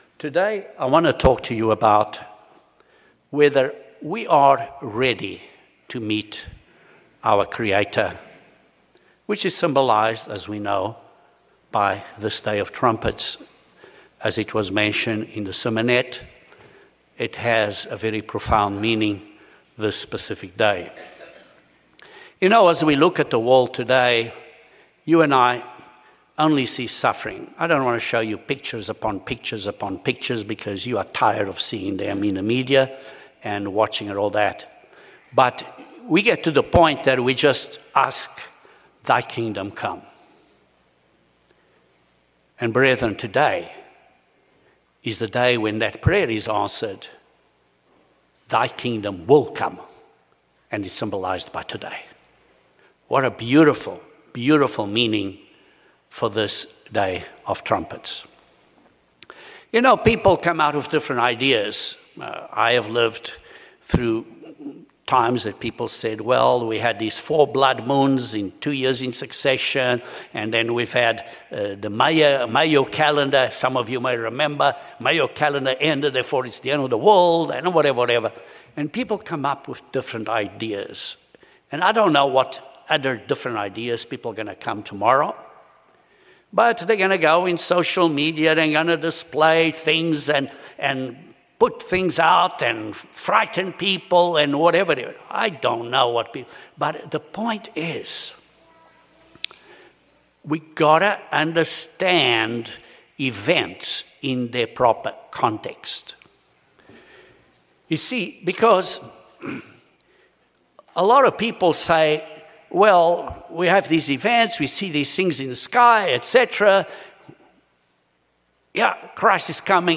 The Day of Trumpets symbolizes the Day of the Lord, and most specifically, the second coming of Christ. The sermon outlines the prophetic events from Revelation and Christ’s messages in a clear and logical way.